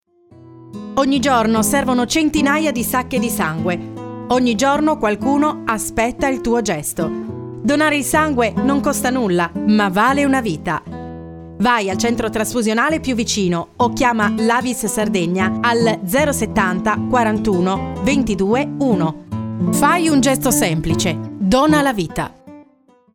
Spot campagna sensibilizzazione "Donazione Sangue 2025"